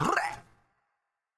Tm8_Chant32.wav